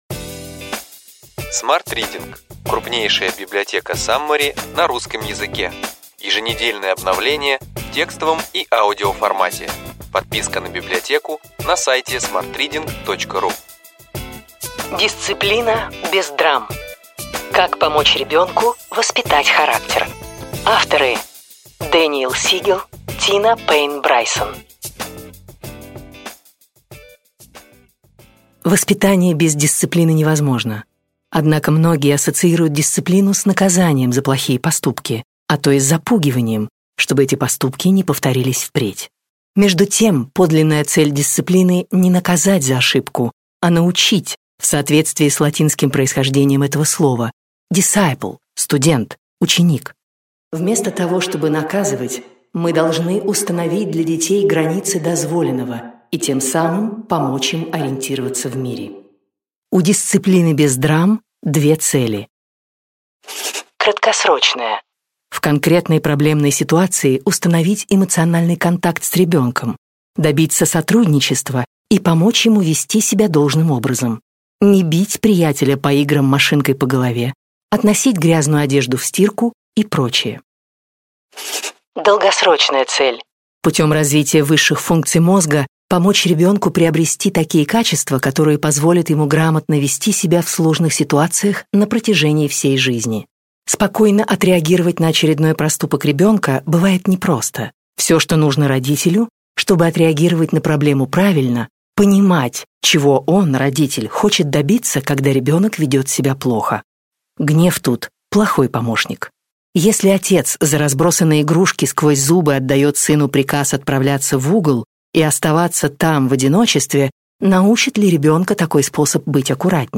Аудиокнига Ключевые идеи книги: Дисциплина без драм. Как помочь ребенку воспитать характер.